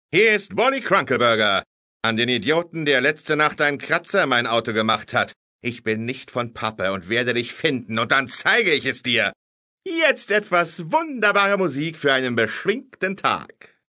In addition to these in-game texts many audio files were recorded that give the player useful hints and information about recent news. Furthermore, there are 11 different radio DJs to lighten the "daily grind" in the moviemaking industry. Depending on the in-game date, patriotic paroles, cool chat and esoteric soul comfort sound through the air.